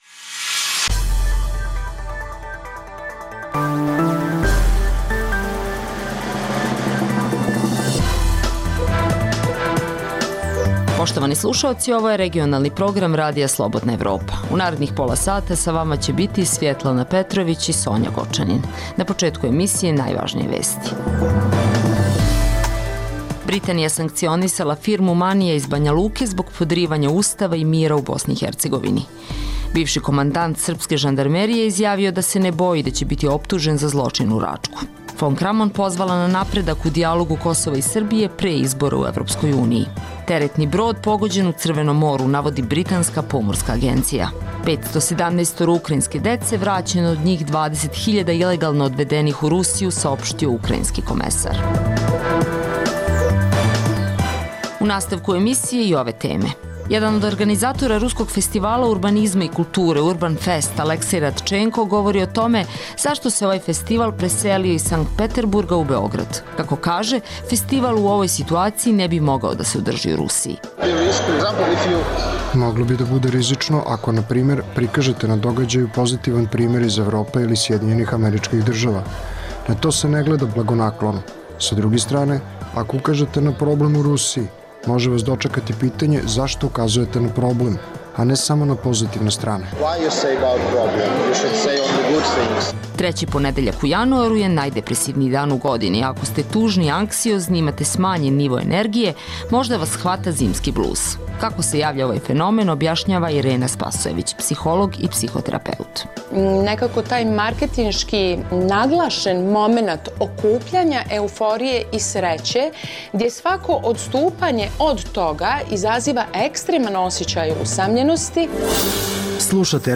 Reportaže iz svakodnevnog života ljudi su takođe sastavni dio “Dokumenata dana”.